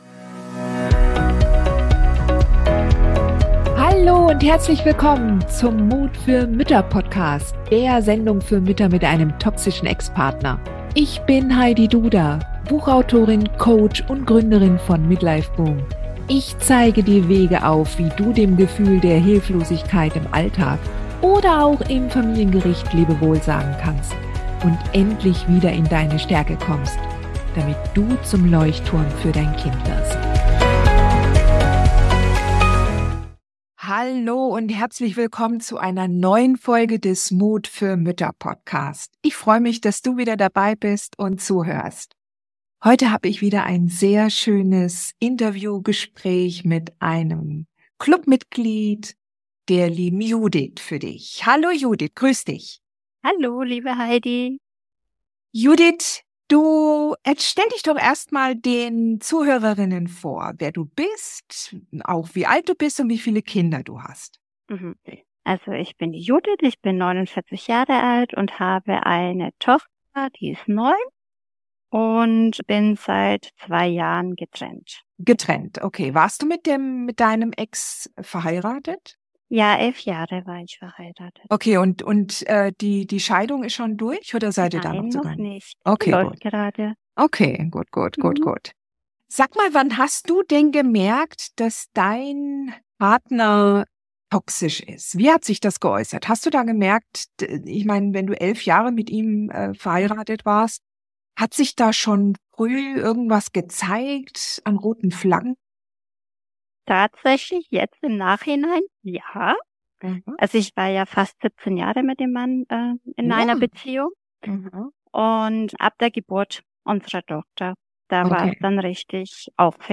Ein weiteres Interview mit einem Club der mutigen Mütter Mitglied!